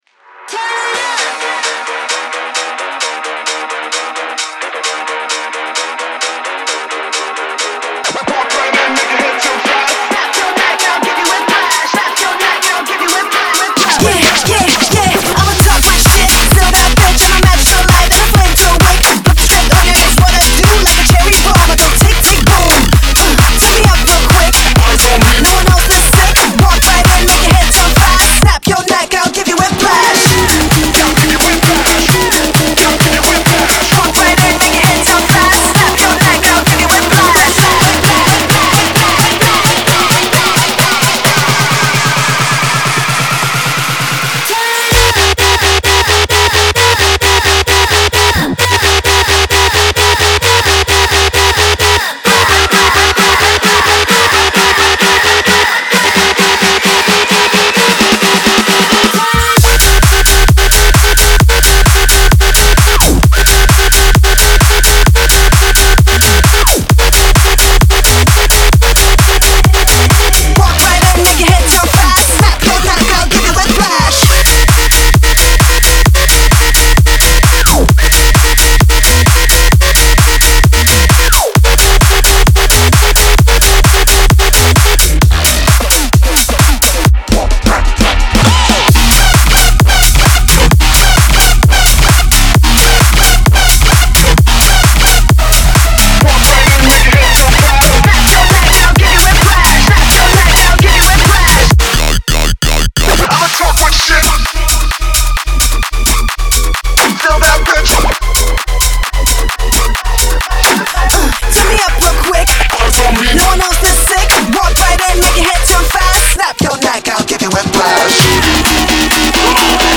BPM131-131
Audio QualityPerfect (High Quality)
Dubstep song for StepMania, ITGmania, Project Outfox
Full Length Song (not arcade length cut)